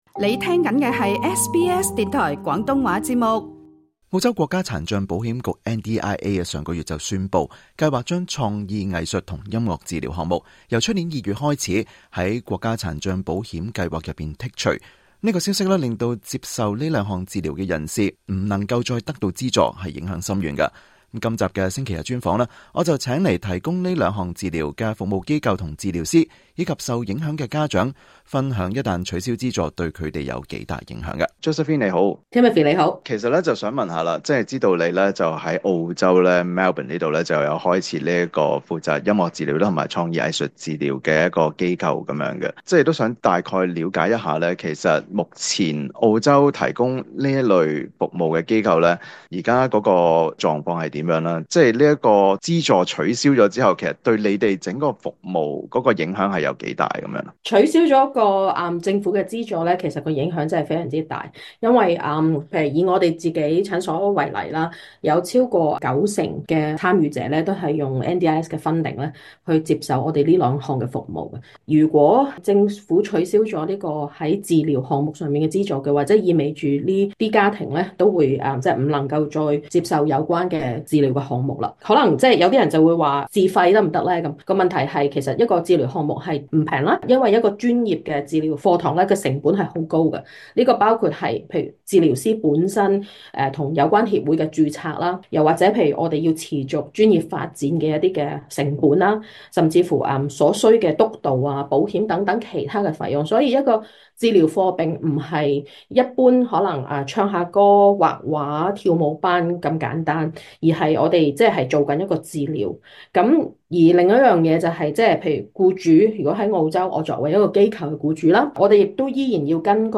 今集【星期日專訪】，提供這兩項治療服務的機構及治療師，以及受影響的家長，會為大家解釋一旦取消這項資助，對他們有多大影響。